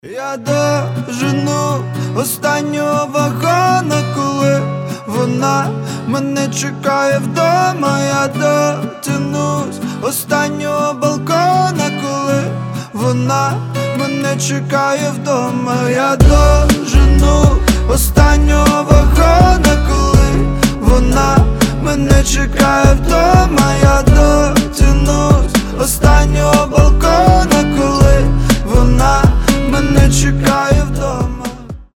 • Качество: 320, Stereo
красивые
дуэт